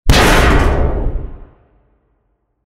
Golpe fuerte a un chapa metálica
golpe
metal
Sonidos: Industria